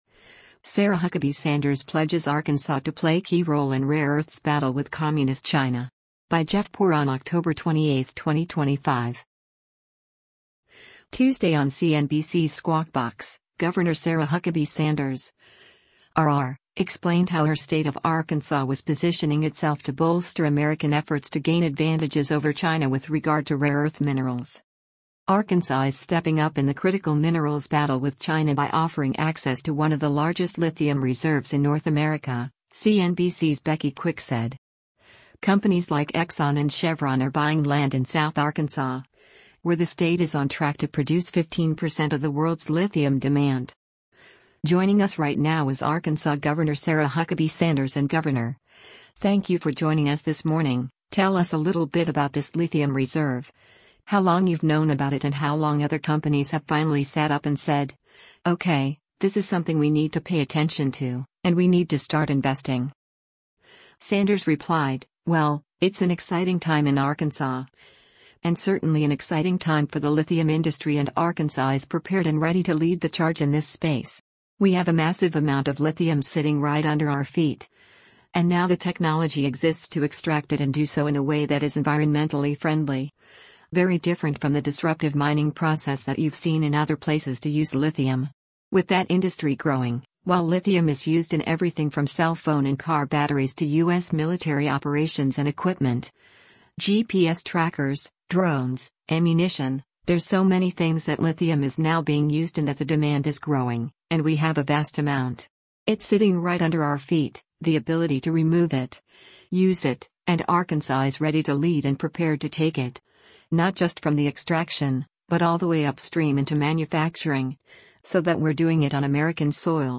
Tuesday on CNBC’s “Squawk Box,” Gov. Sarah Huckabee Sanders (R-AR) explained how her state of Arkansas was positioning itself to bolster American efforts to gain advantages over China with regard to rare earth minerals.